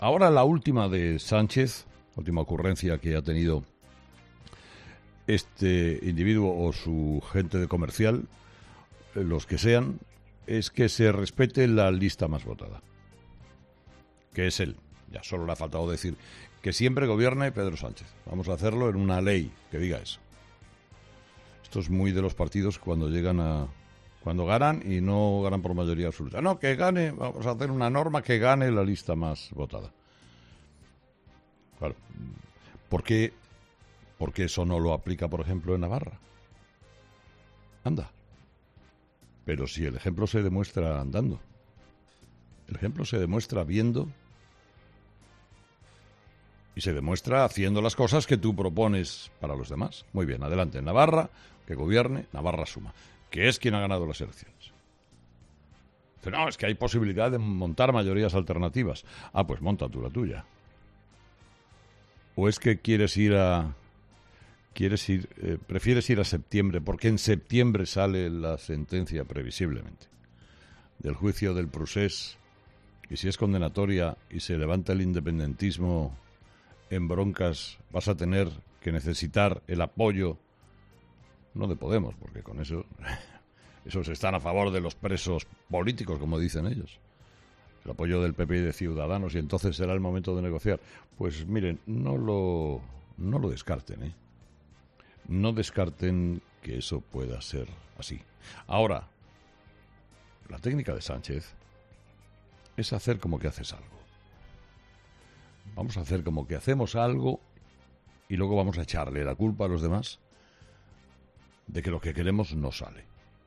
Monólogo de las 8 de Herrera
Este viernes en su editorial, Carlos Herrera ha dedicado unos minutos a esta proposición del secretario general del PSOE.